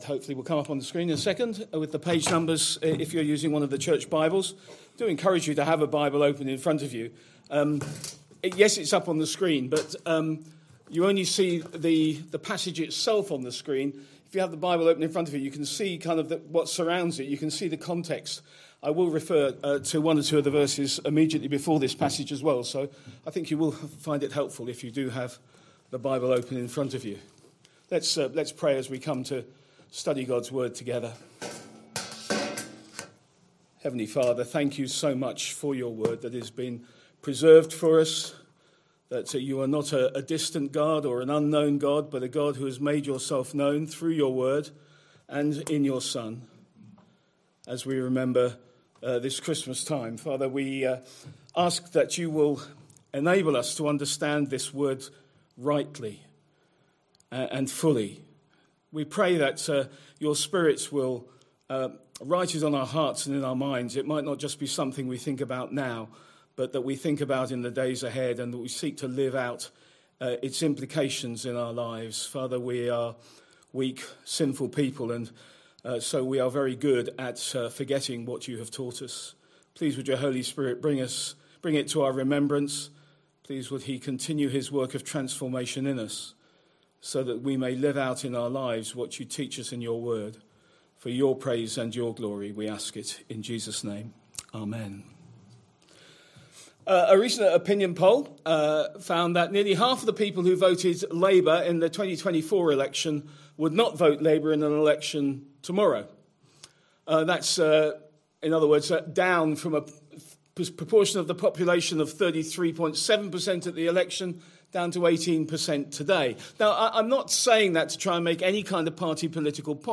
Christ Church Sermon Archive
Sunday PM Service Sunday 21st December 2025 Speaker